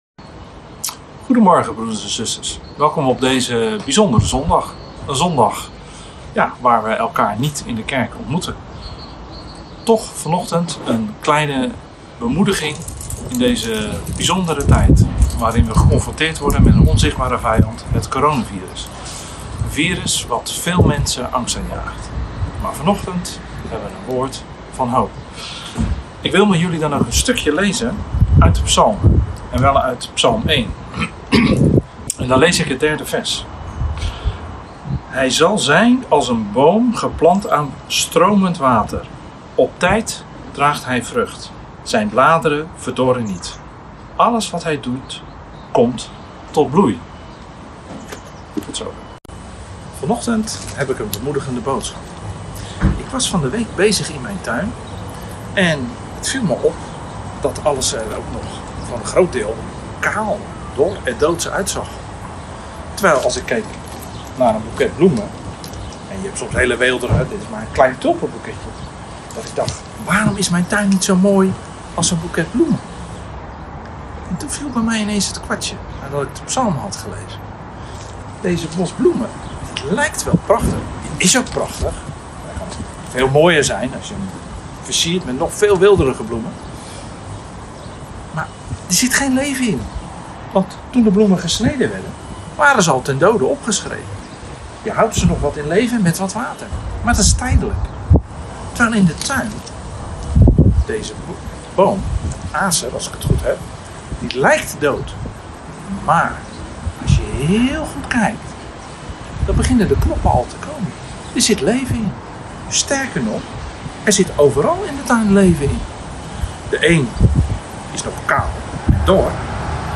Online boodschap
Mag ik tot een zegen zijn Overdenking 1.mp3